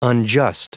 Prononciation du mot unjust en anglais (fichier audio)
Prononciation du mot : unjust